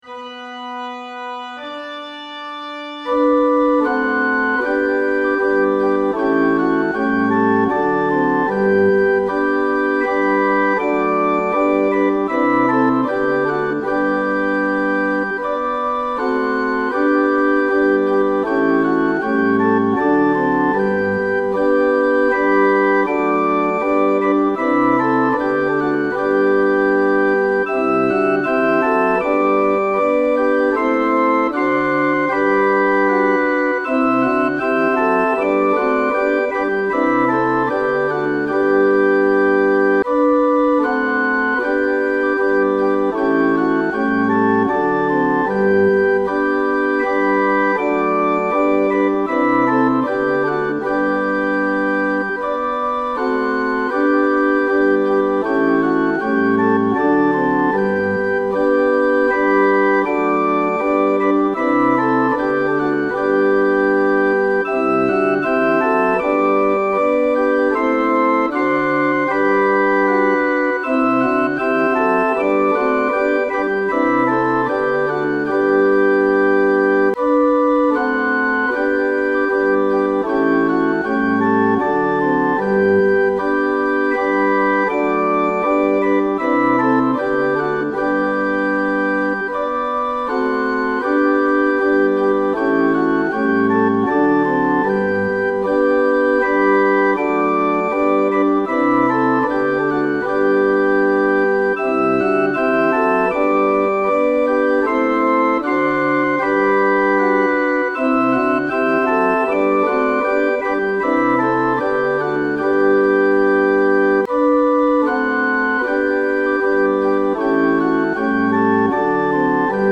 Once In Royal David’s City 4 verses intro | Ipswich Hospital Community Choir